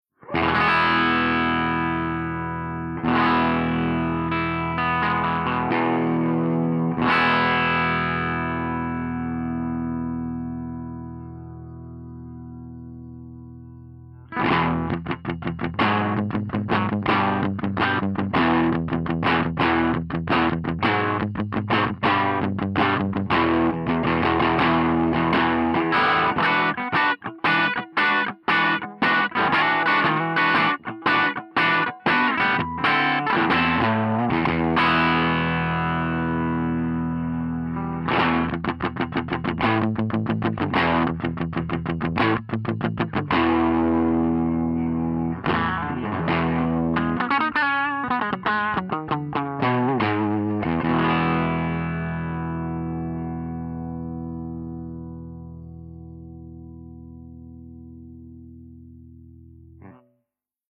049_FENDER75_DRIVECHANNEL_HB.mp3